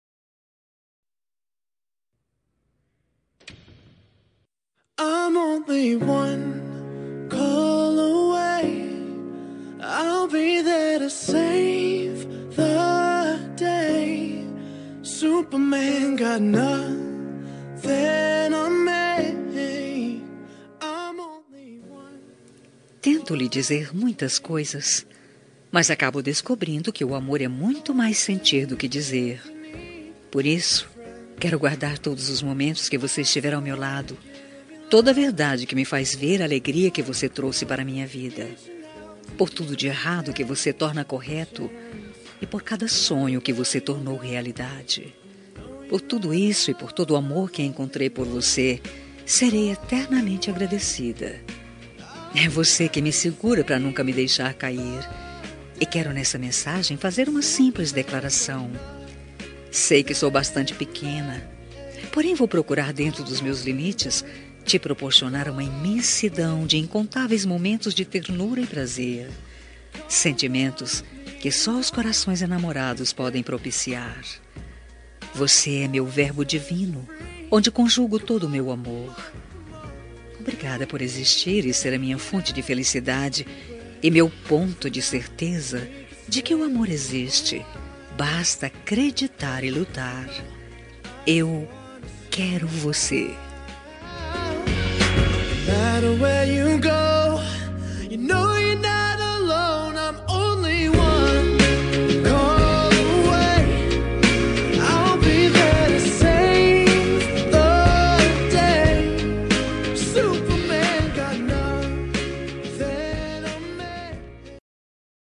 Telemensagem Romântica -Voz Feminina – Cód: 4148 – Linda